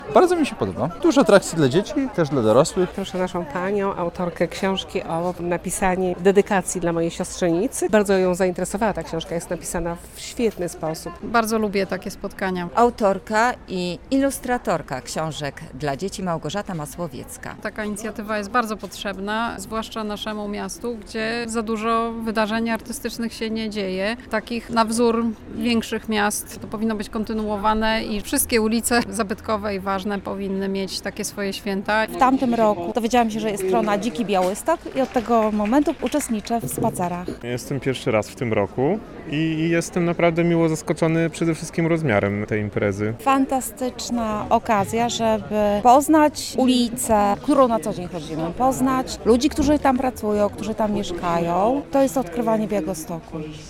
Drugie Święto Ulicy Warszawskiej - relacja